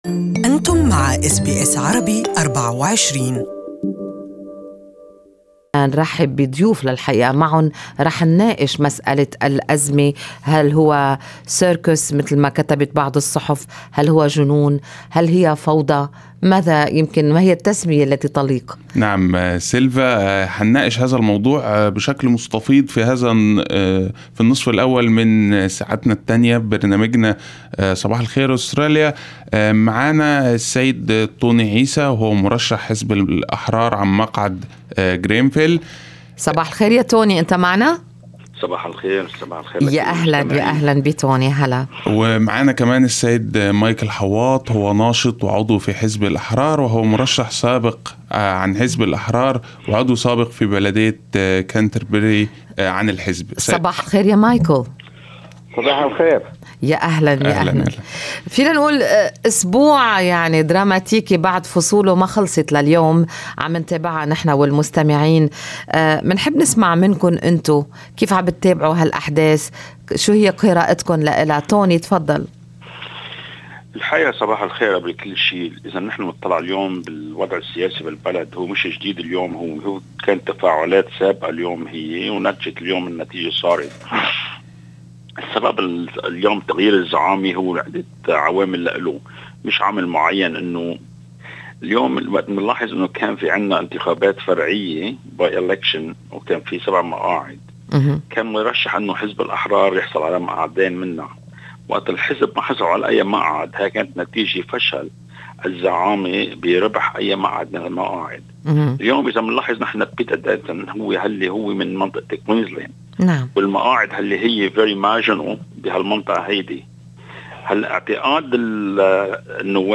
Current and former liberal candidate discuss the future of the party as party’s senior members change loyalties